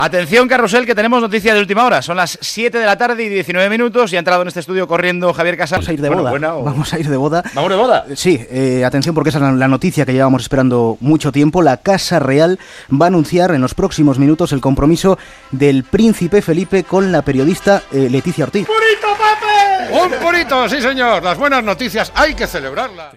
Hora, avenç informatiu: la Casa Real farà públic el noviatge del prícep Felipe de Borbón
Esportiu